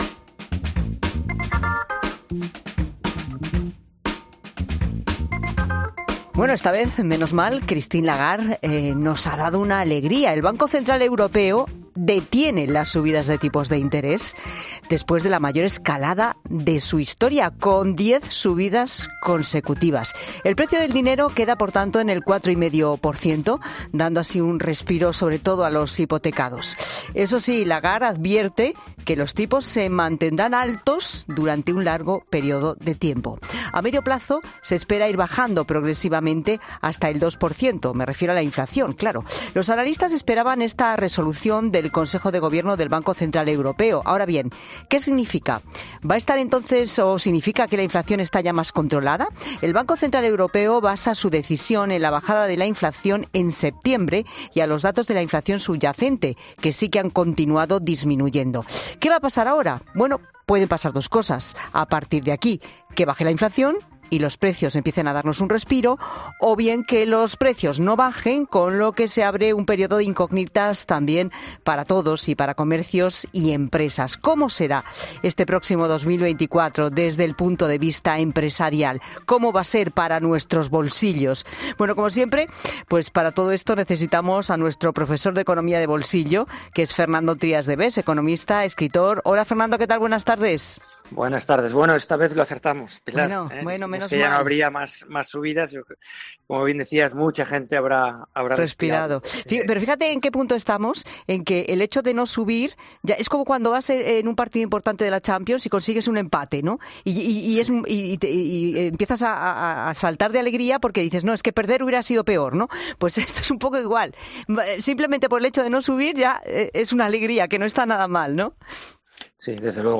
Un economista explica qué escenario nos espera en 2024 con la estabilidad de los tipos de interés